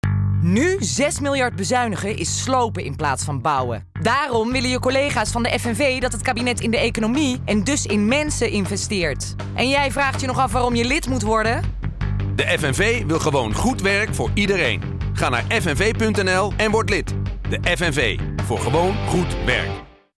Commercials:
FNV (stoer):